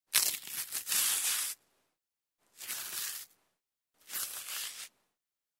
Звук ломания банана пополам